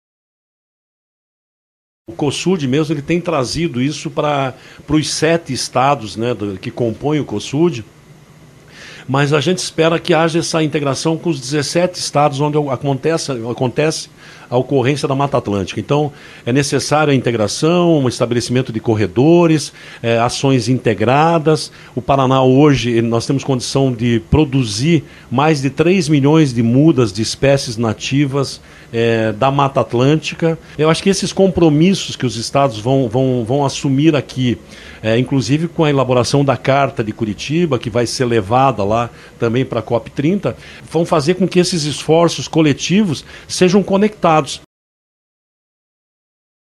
Na cerimônia de abertura, realizada pela manhã no Teatro Guaíra, o Paraná apresentou iniciativas voltadas à sustentabilidade.
Para o diretor-presidente do Instituto Água e Terra (IAT), Everton Souza, a Carta ganhará peso graças à integração.